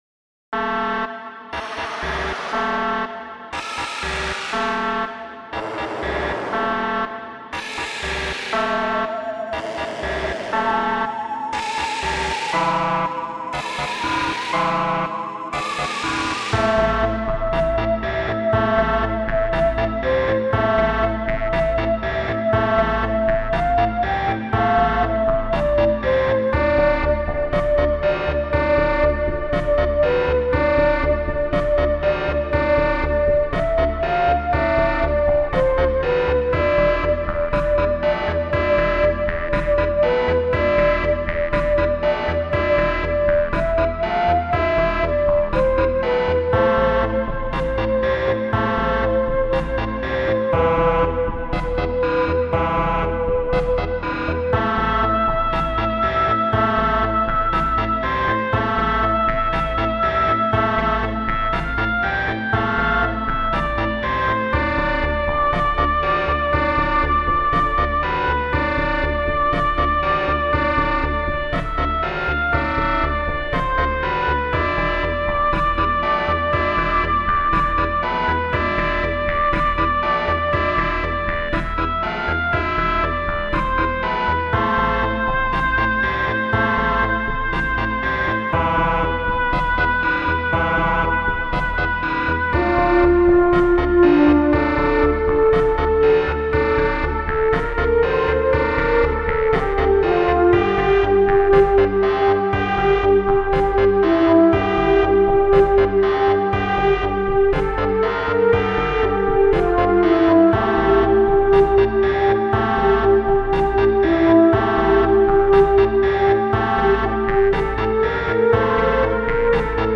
Транс медитация.